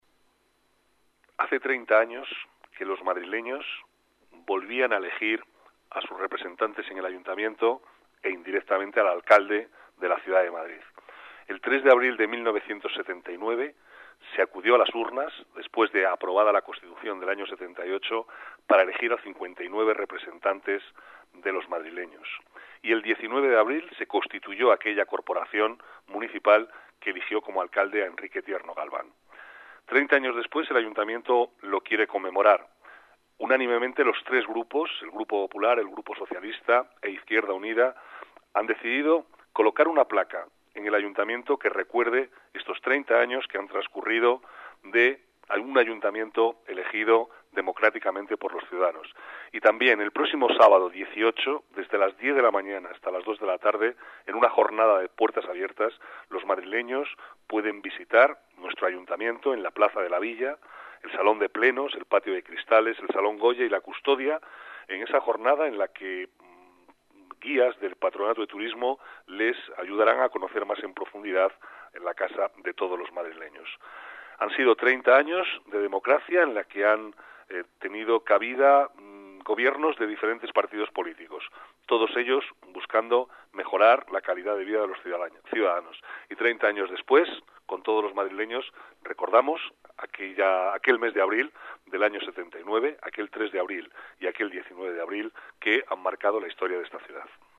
Nueva ventana:Declaraciones del vicealcalde, Manuel Cobo: Aniversario Ayuntamientos Democráticos